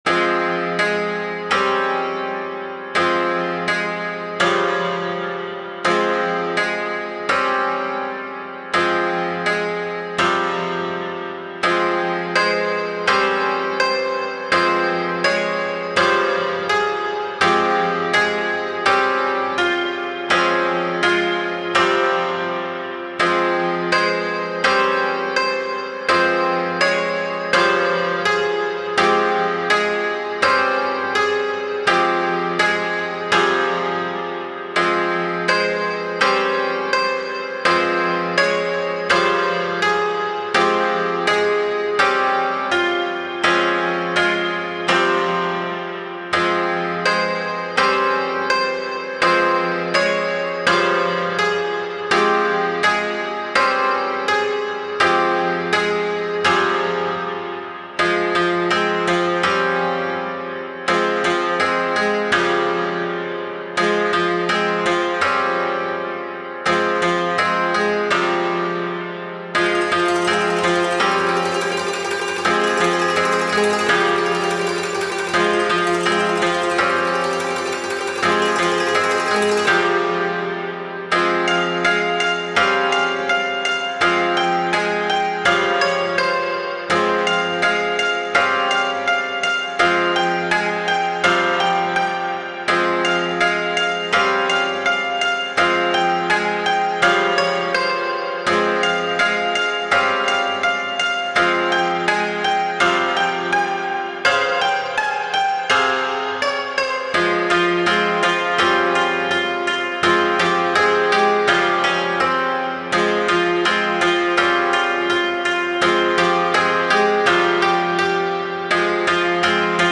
不気味な雰囲気の琴の楽曲。
タグ: 不気味/奇妙 和風 怪しい 暗い 琴 音少なめ/シンプル コメント: 不気味な雰囲気の琴の楽曲。